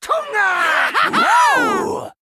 行为语音下载
VO_LOE_089_Play_Trio.ogg